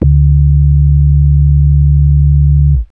Sub bass (PCM) 33k